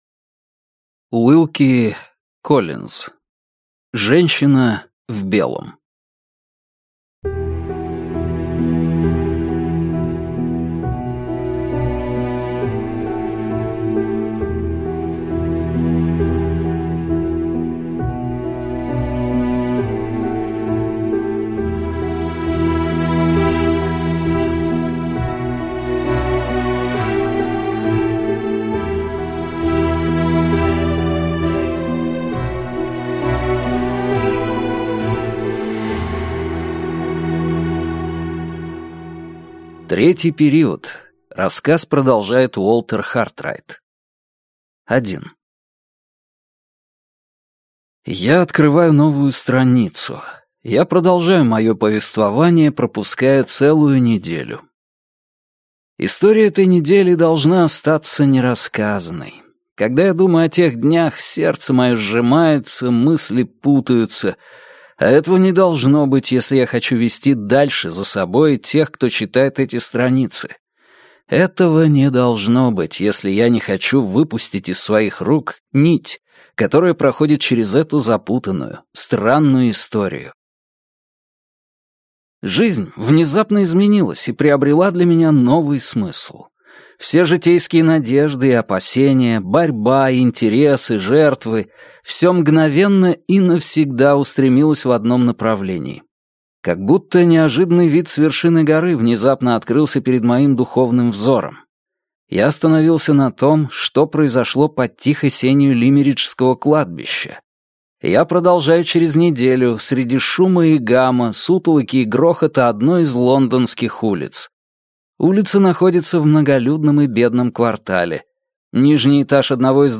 Аудиокнига Женщина в белом. Третий период | Библиотека аудиокниг